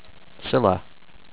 SILL-uh